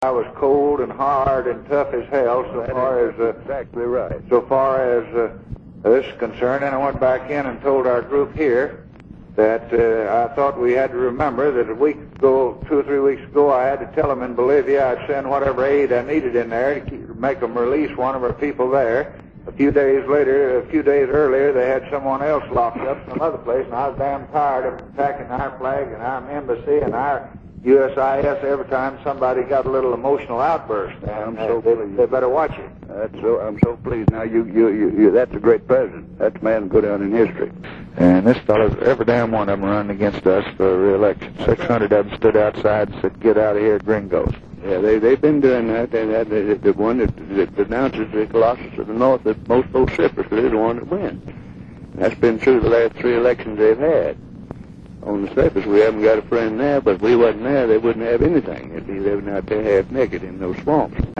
Few of his advisors shared such a hard-line view, so he turned for support to his Senate patron, Georgia senator Richard Russell, in these excerpts from January 10 and 11.